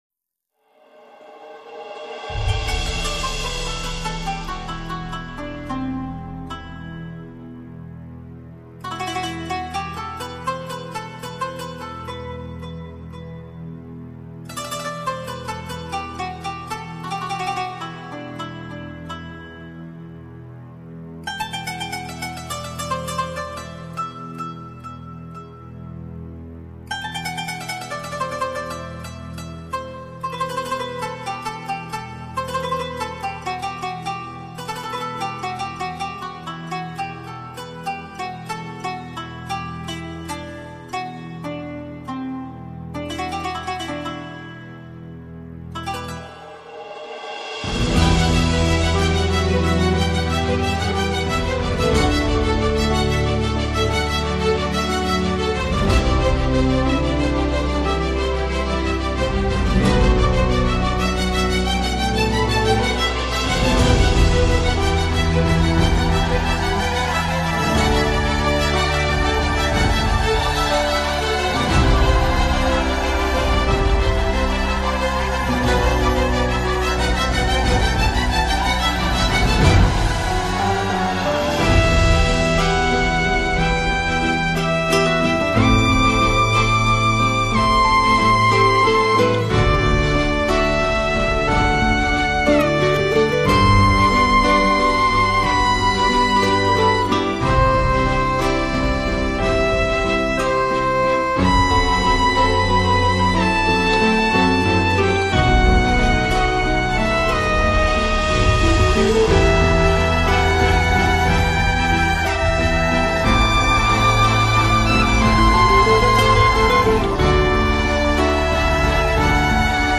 زفة عروس